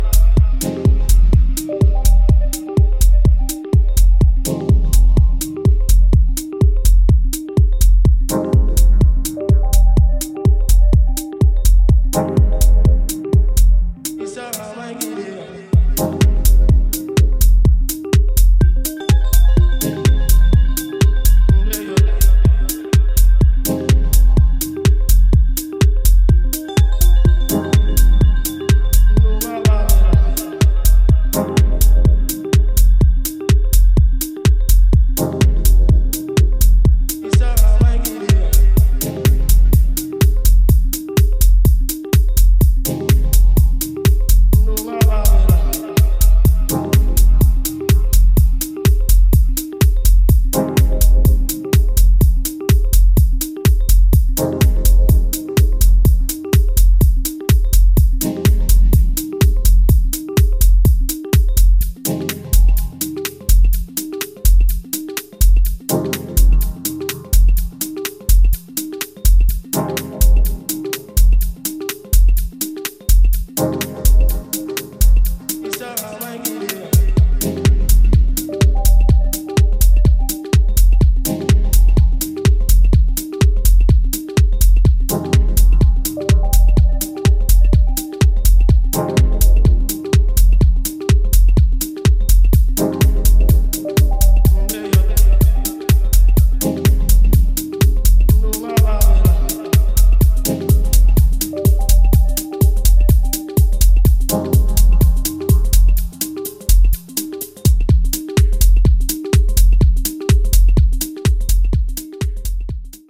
gorgeously light touch techno workout